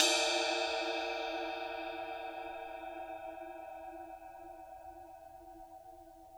susCymb1-hitstick_pp_rr2.wav